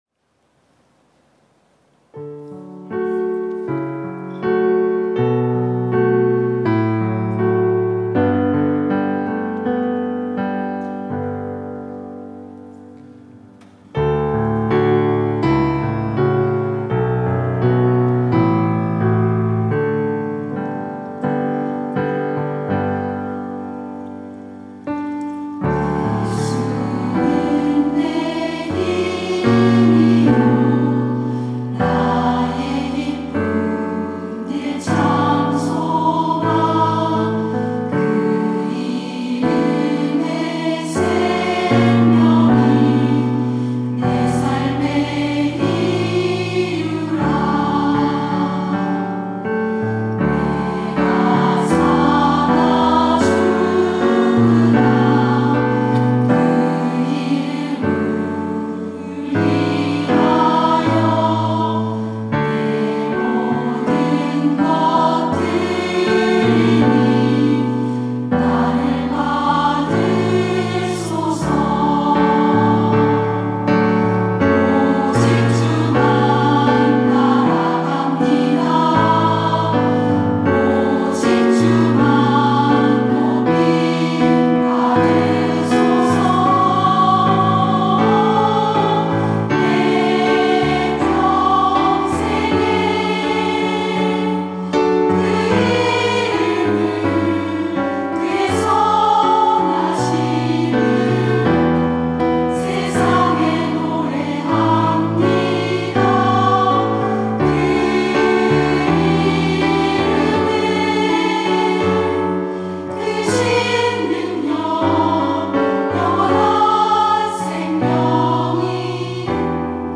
특송과 특주 - 내 삶의 이유라